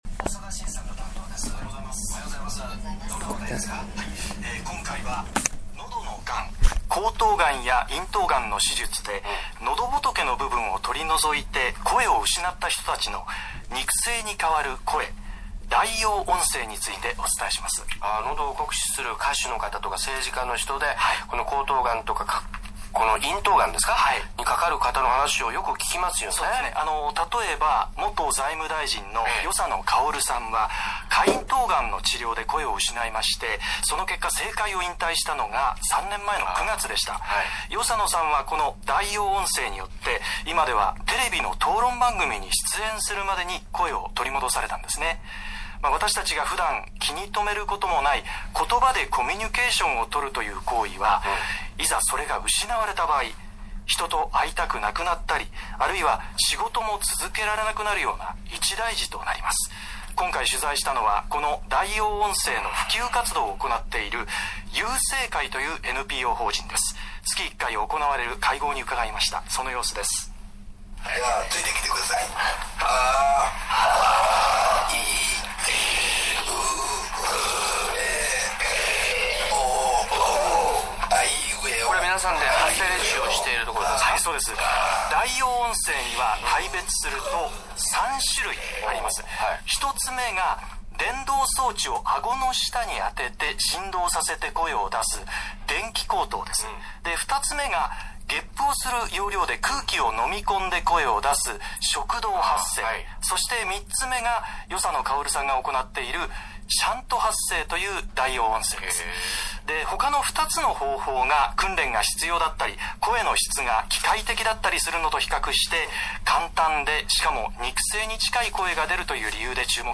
TBSﾗｼﾞｵ【土曜朝イチエンタ。堀尾正明＋PLUS！】に悠声会が紹介されました(音声)